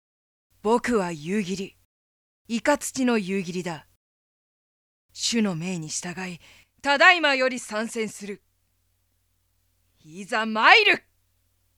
【サンプルセリフ】